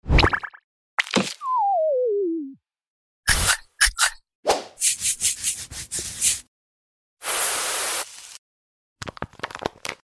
Sad Face Soothing ASMR Squishy Sound Effects Free Download